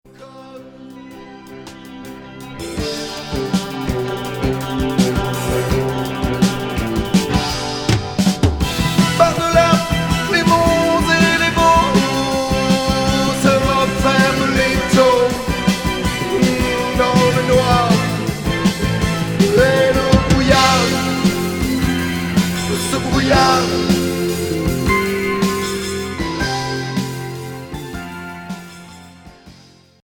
Rock progressif Unique 45t retour à l'accueil